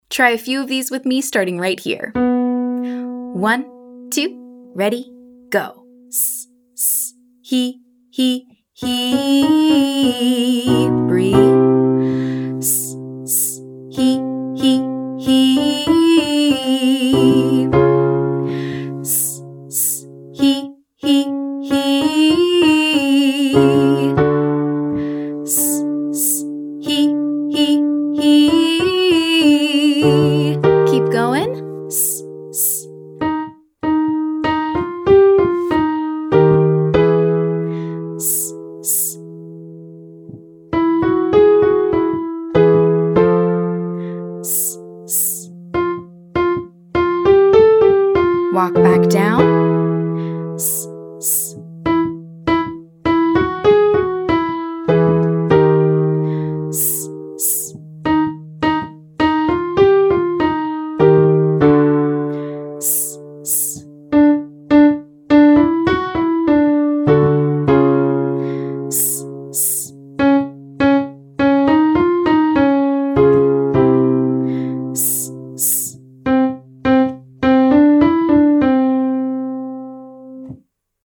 Warmup - Online Singing Lesson
Exercise: Hiss to hum (single pitch, then student-led vocalization)
Two hiss pulses, two hee pulses, and a 12321 pattern.
Exercise: Hiss x2, Hee x2, Hee 12321